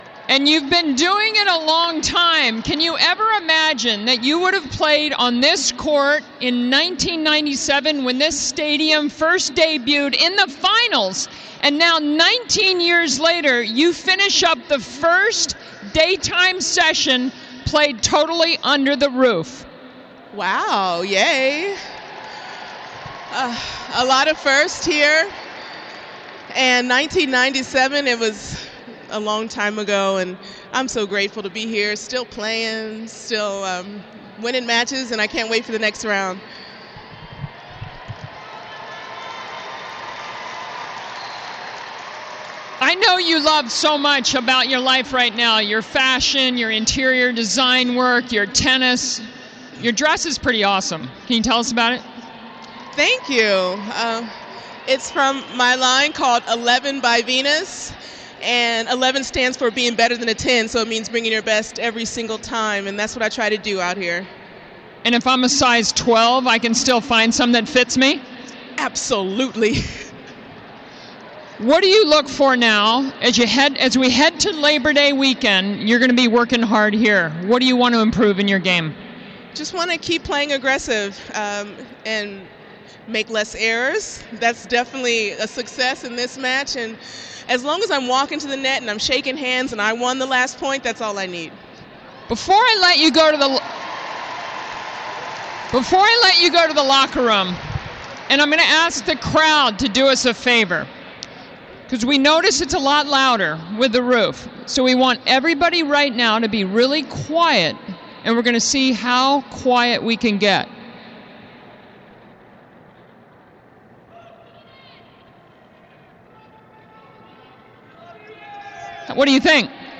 Venus post-match interview